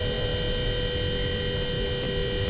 starter_loopold.wav